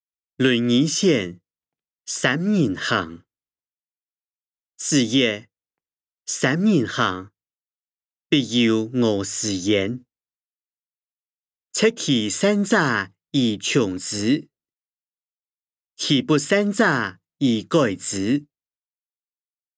經學、論孟-論語選．三人行音檔(四縣腔)